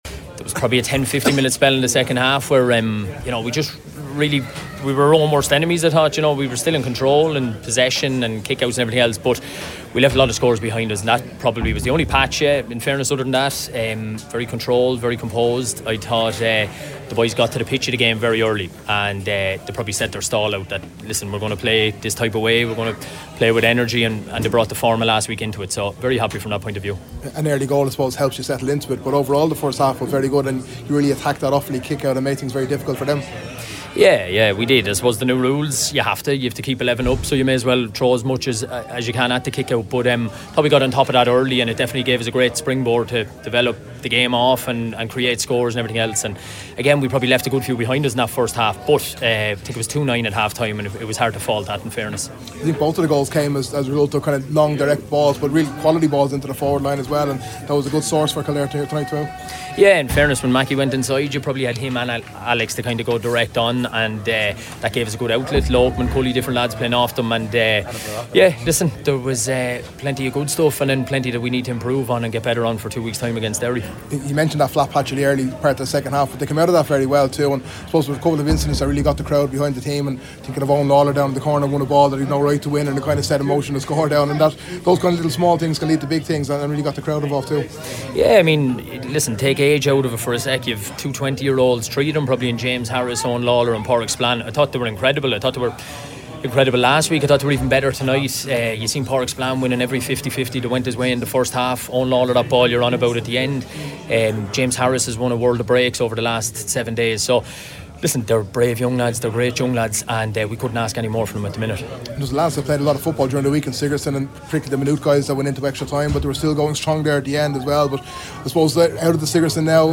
Interview
post-match vs Offaly